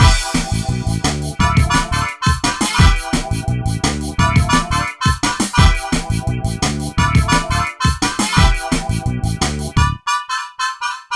13 Breakdance-c.wav